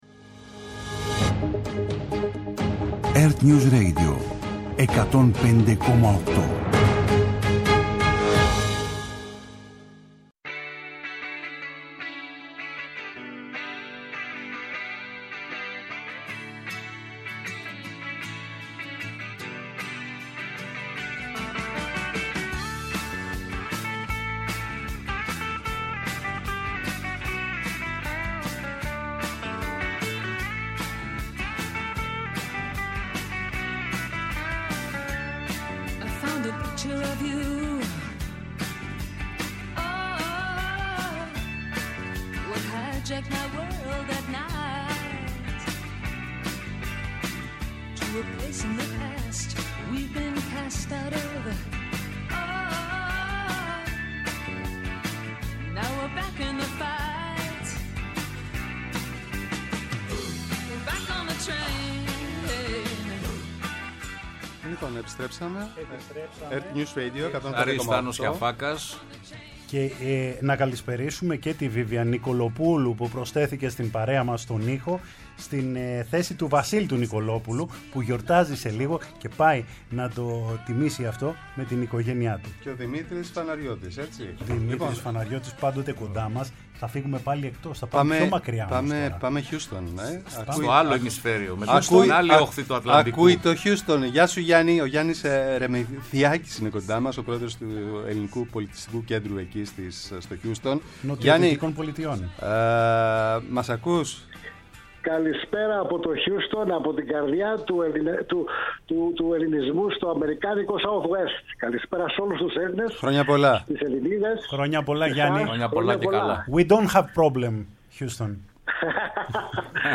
Οι τρεις δημοσιογράφοι που κάθε βράδυ παρουσιάζουν την επικαιρότητα στο ΕΡΤnews Radio, αποχαιρετούν τη χρονιά που φεύγει και καλοσωρίζουν τη χρονιά που έρχεται στήνοντας μια ξεχωριστή ραδιοφωνική γιορτή με ζωντανές συνδέσεις, εκλεκτούς καλεσμένους και ευχές. Στις 12 ακριβώς η αλλαγή του χρόνου σε απευθείας σύνδεση με το κέντρο της Αθήνας.